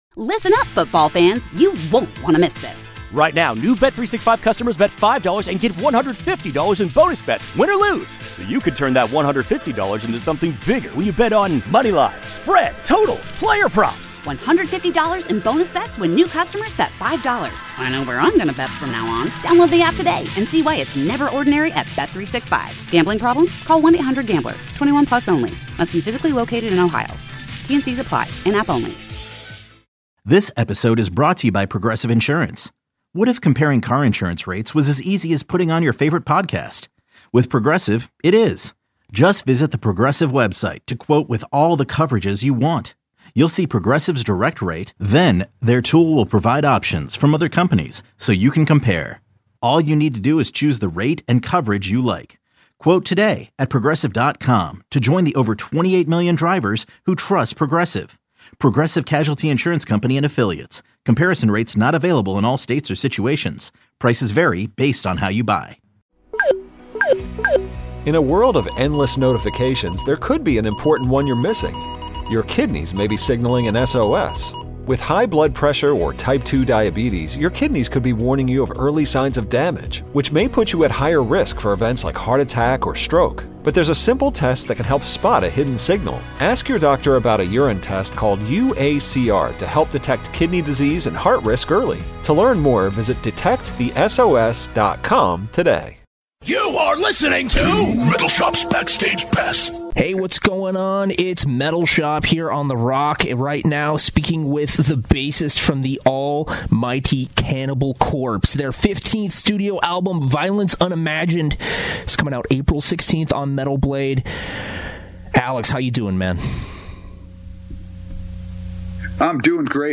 Cannibal Corpse will be releasing “Violence Unimagined” in April, so I chatted with bassist Alex Webster about the album, staying consistent, recording from across the nation, living in Portland, working with Erik Rutan full time and much more.